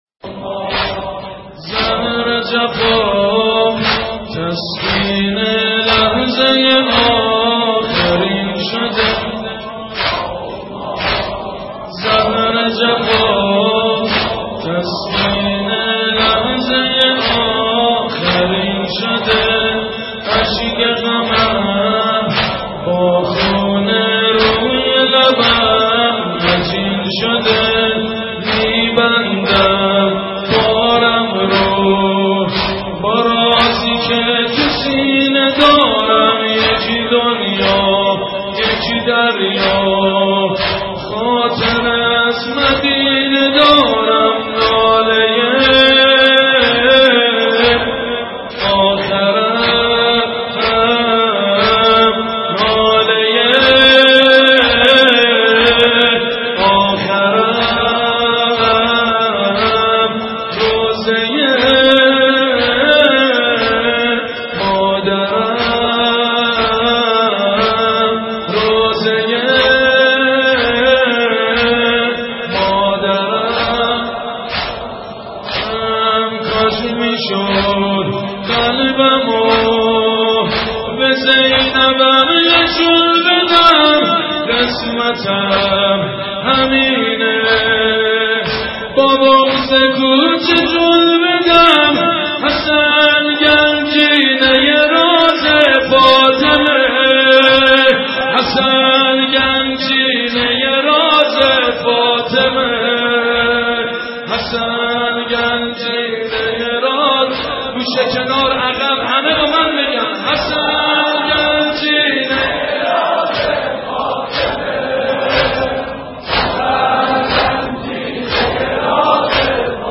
مراسم نوحه خوانی